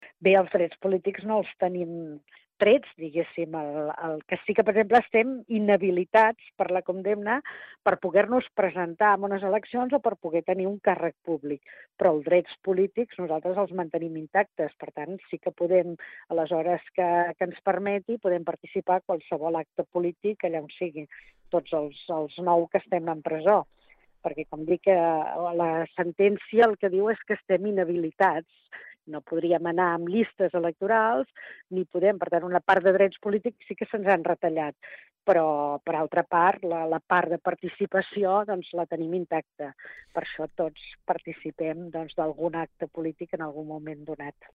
Entrevistes Supermatí
A l’entrevista del dimarts 9 de febrer vam parlar amb l’exconsellera Dolors Bassa, sobre com està passant el tercer grau i com encara la jornada electoral del 14 de Febrer.